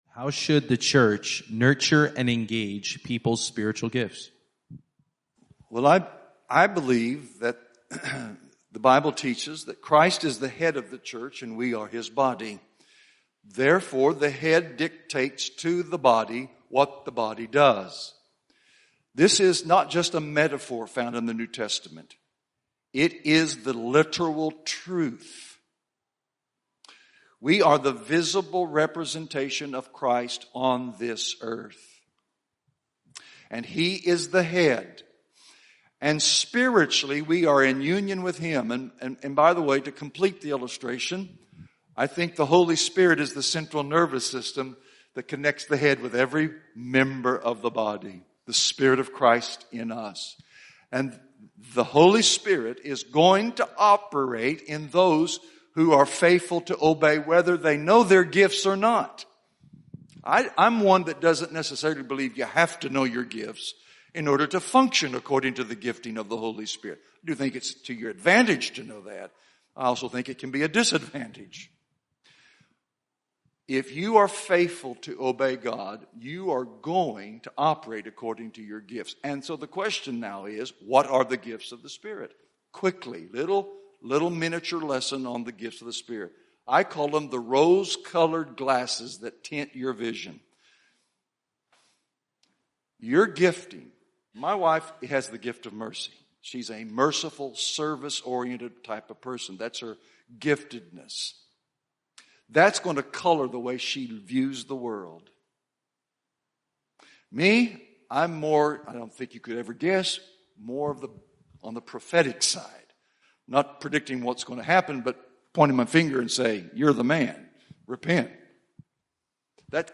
Q&A: How Can the Church Nurture and Encourage Spiritual Gifts?